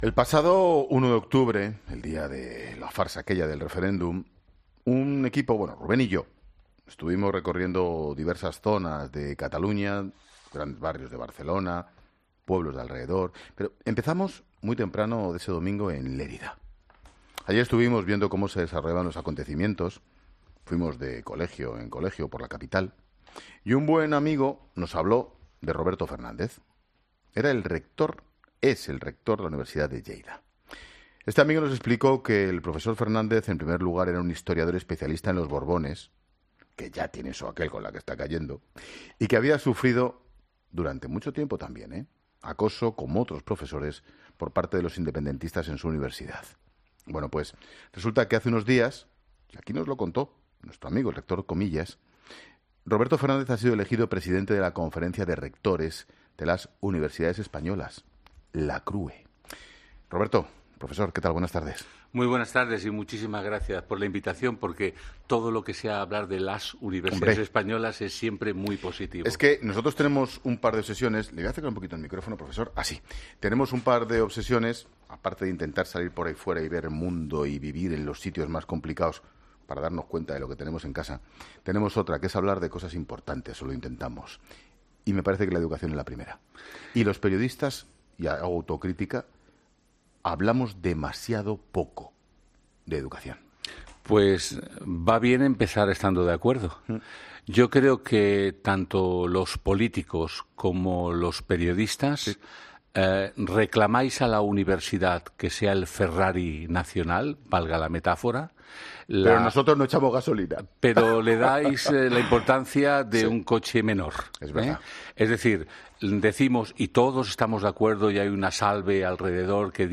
Roberto Fernández, presidente de la Conferencia de Rectores de las Universidades Españolas